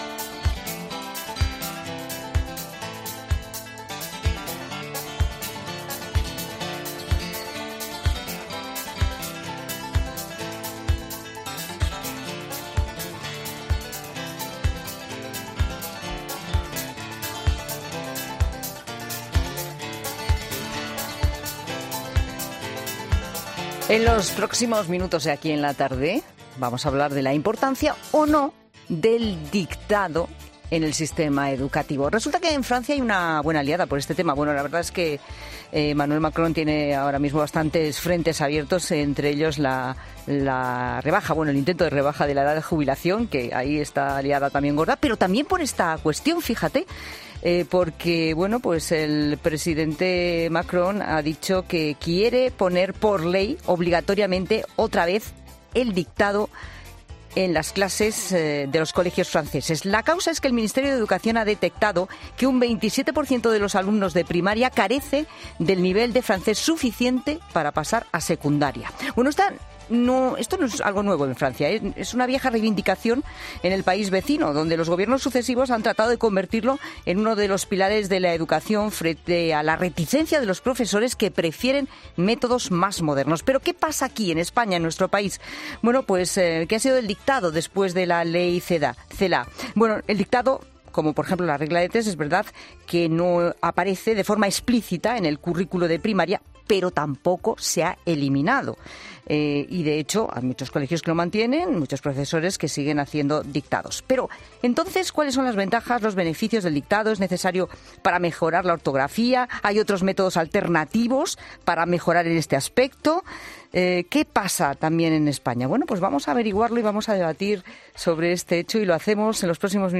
¿Se ha quedado obsoleto el dictado? Lo analizamos en 'La Tarde' con un profesor de infantil y una de universidad